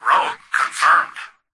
"Rogue confirmed" excerpt of the reversed speech found in the Halo 3 Terminals.
H3_tvox_fix1_rogueconfirmed_(unreversed).mp3